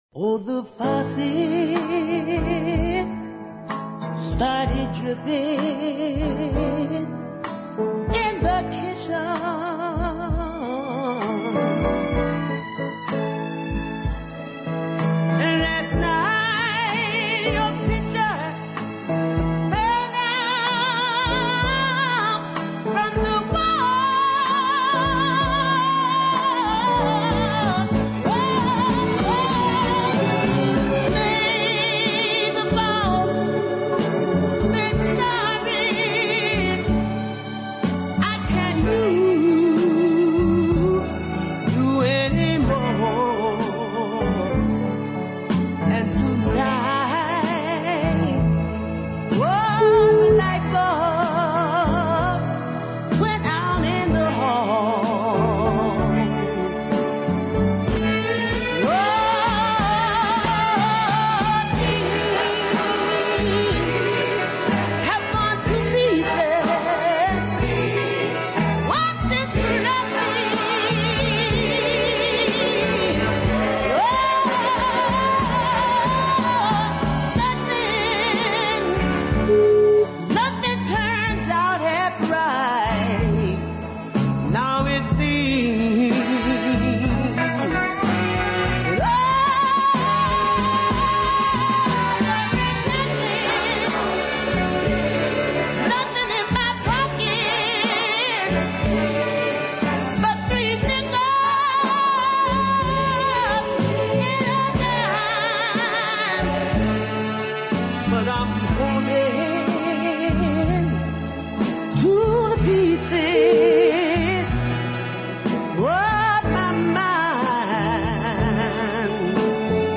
more countrified than the previous releases – weepy strings